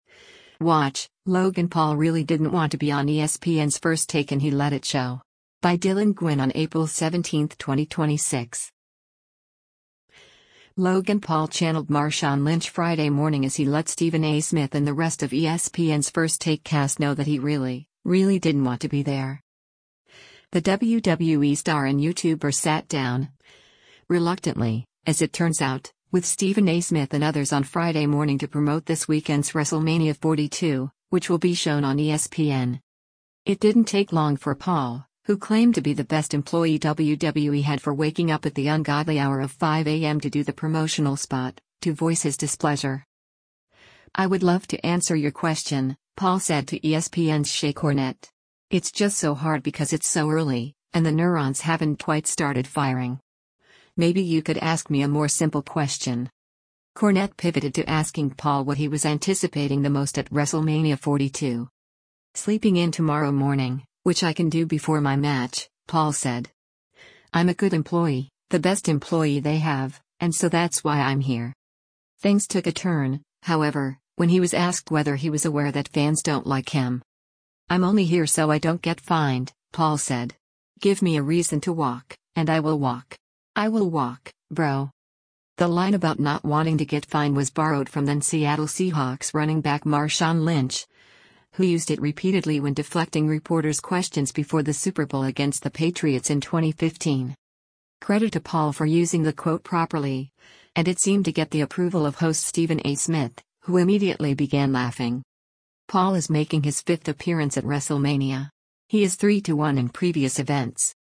Credit to Paul for using the quote properly, and it seemed to get the approval of host Stephen A. Smith, who immediately began laughing.